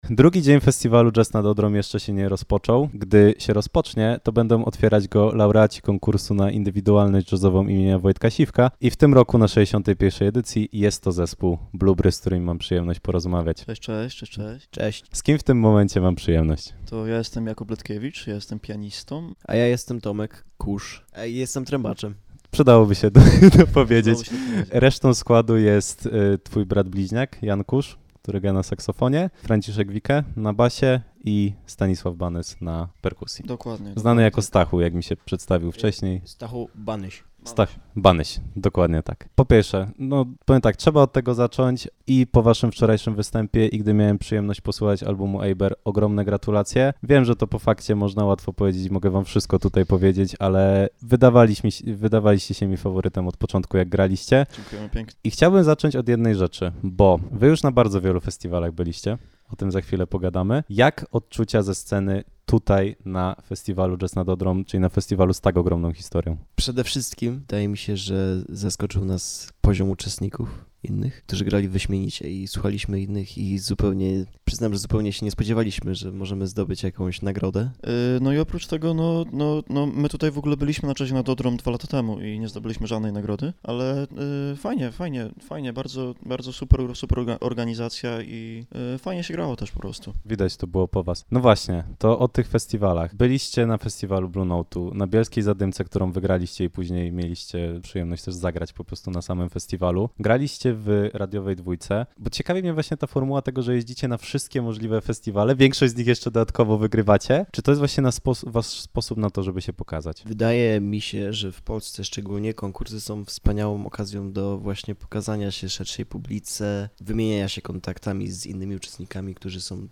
Blu/Bry zwycięzcami konkursu 61. festiwalu Jazz nad Odrą - Wywiad - Radio LUZ